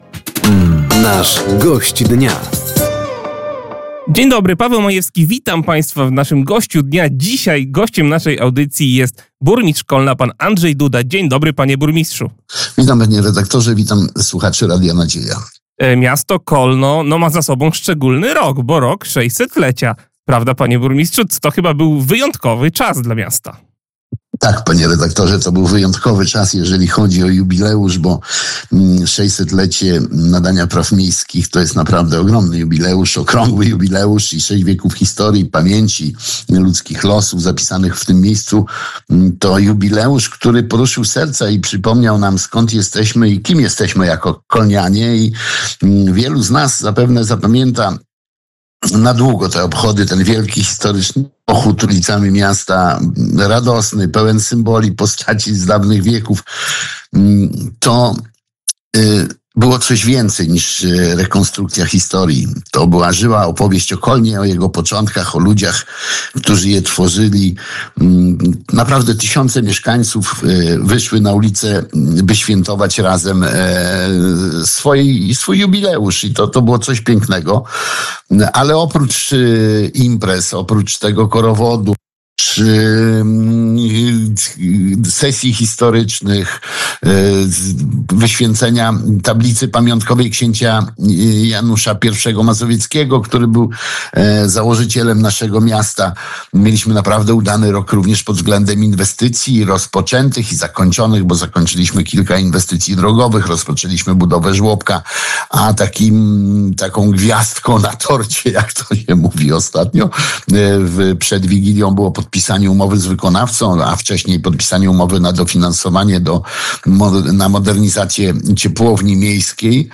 Gościem Dnia Radia Nadzieja był burmistrz Kolna Andrzej Duda. Samorządowiec podsumował 2025 rok oraz powiedział o budżecie miasta na rok przyszły.